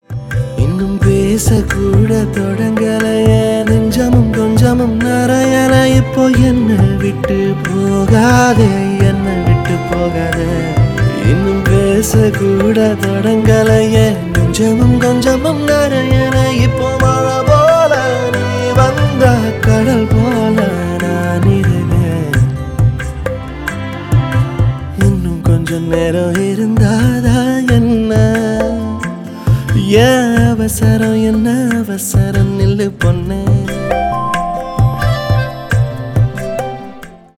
поп
спокойные , медленные